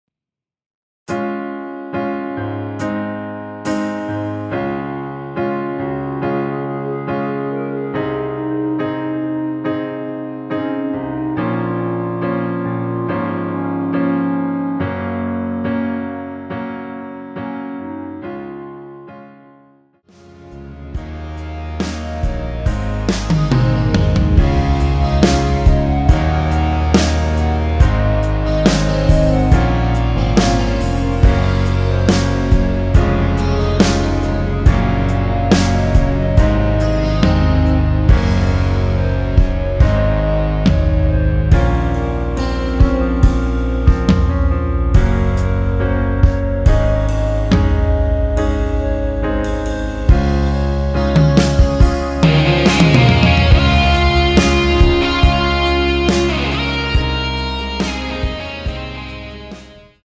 노래하기 편하게 전주 1마디 만들어 놓았습니다.(미리듣기 확인)
원키에서(-1)내린 멜로디 포함된 MR입니다.
Db
앞부분30초, 뒷부분30초씩 편집해서 올려 드리고 있습니다.